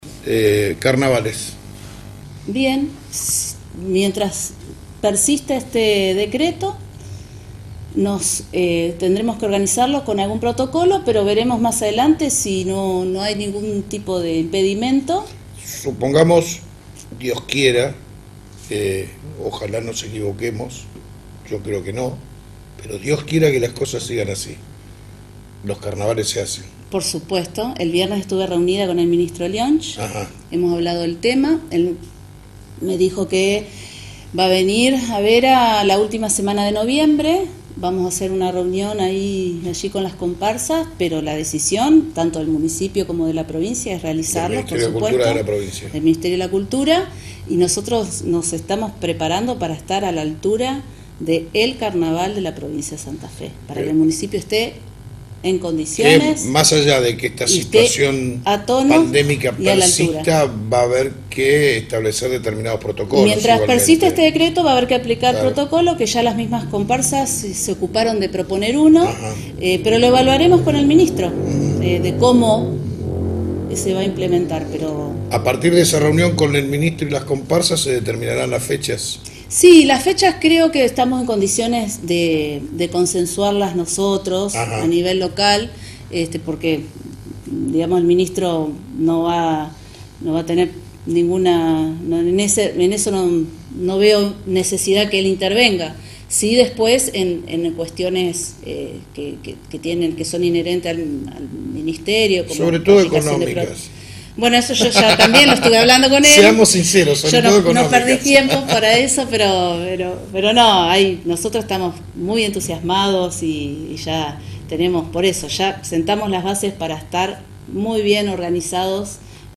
En dialogo con el programa de TV AL MARGEN la Intendenta, Paula Mitre, indicó que, si las actuales condiciones que rigen por la pandemia de Covid-19 se mantienen, en Febrero volverán a realizarse los carnavales locales luego de haberse suspendido en el 2020.